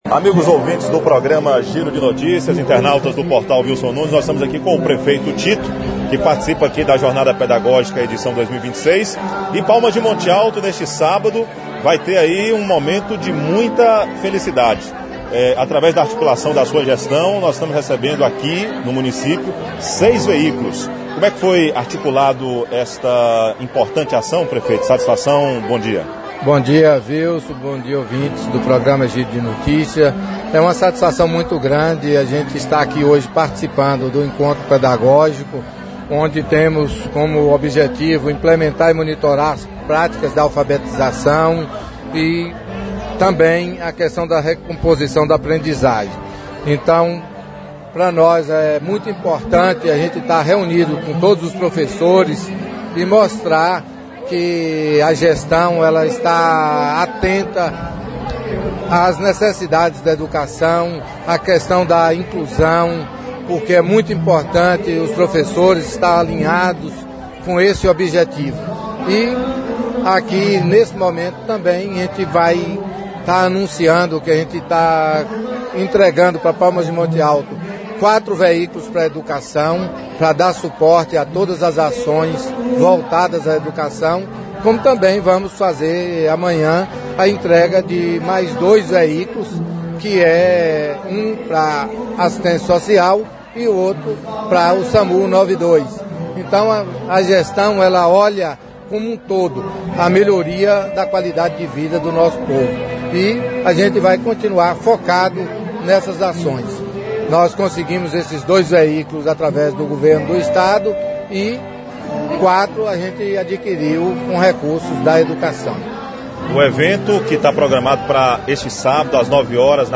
Entrevista de Tito, prefeito de Palmas de Monte Alto (Foto: Reprodução)